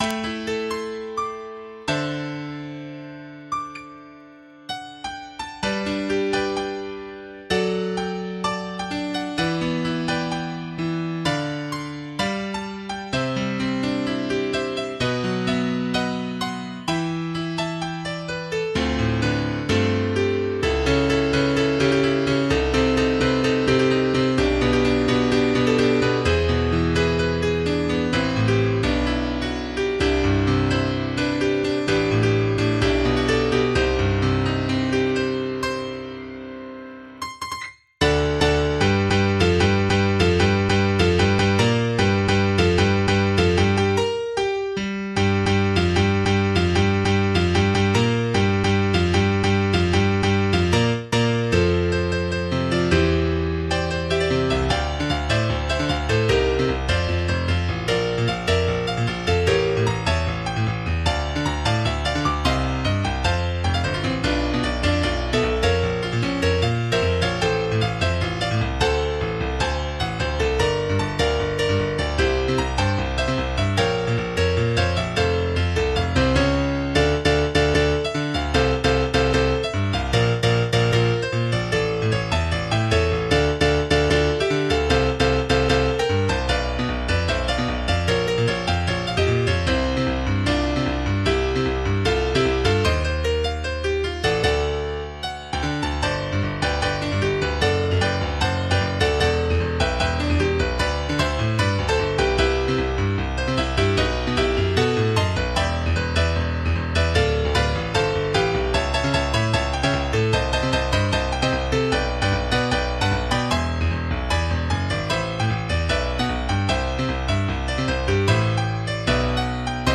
A solo piano transcription